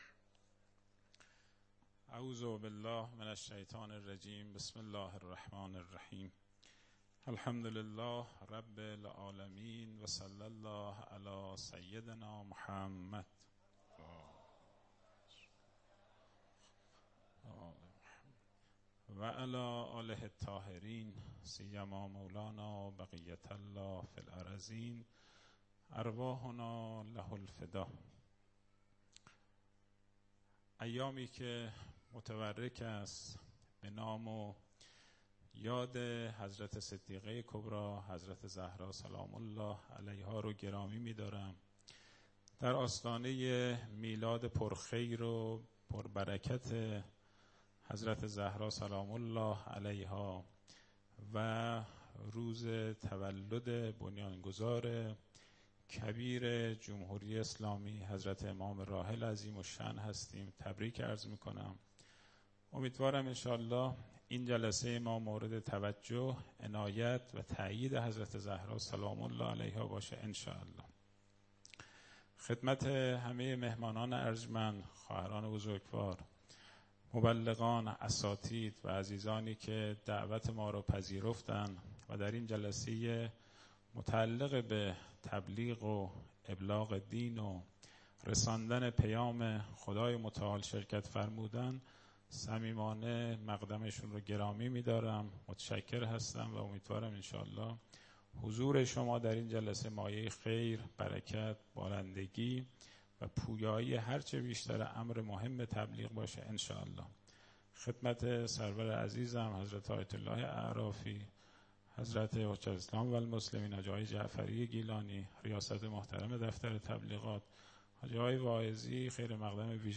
صوت| سخنرانی
در آئین اختتامیه جشنواره ملی نوآوری‌های تبلیغی «جنات»